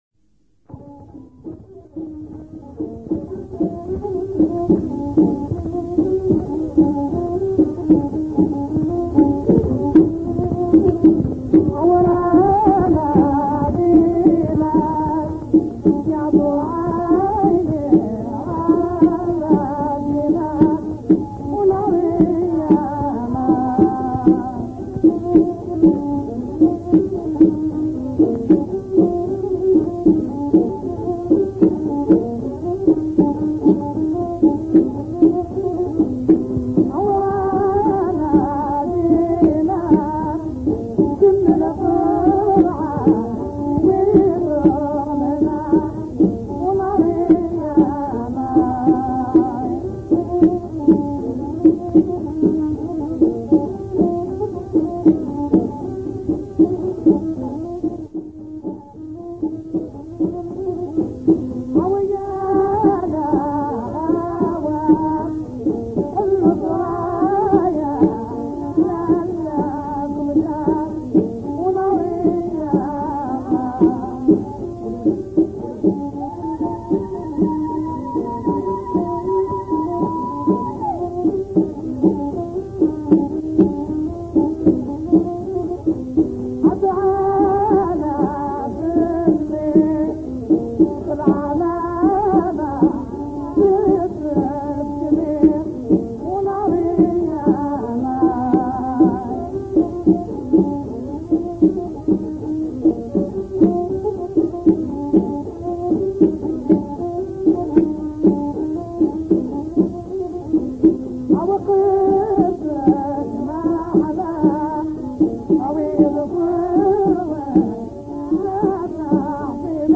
La Musique Çan'a d'Alger. / M'samii : " Rana Djinak "
1- Le rythme d'accompagnement est constant tout au long de la pièce; un rythme qui se présente avec trois percussions bien distinctes : "TacTac(*)Tac(*)(*)" ; "TacTac(*)Tac(*)(*)"; etc.
Ce rythme a bien une "allure" de deux temps.
L'accent est mis sur le 2ème temps et sur le 5ème temps du rythme standard.
Ceci semble plus "logique" comme équilibrage pour cette pièce (la réponse instrumentale est aussi en 16 mesures).